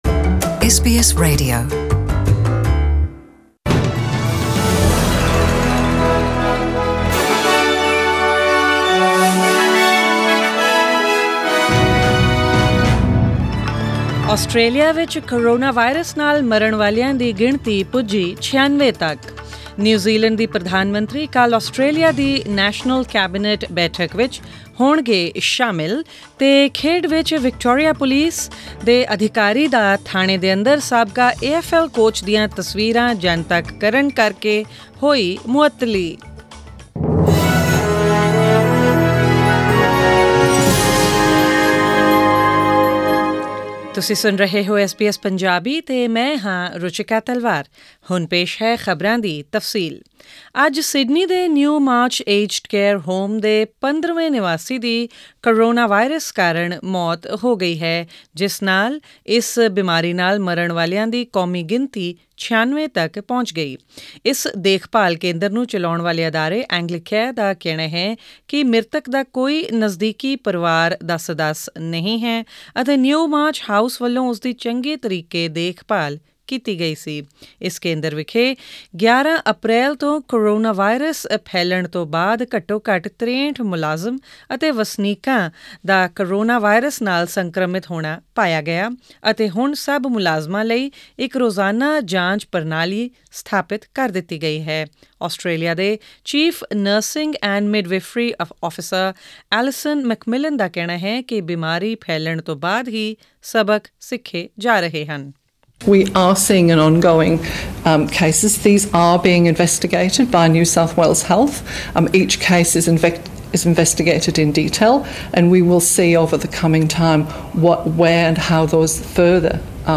Australian News in Punjabi: 4 May 2020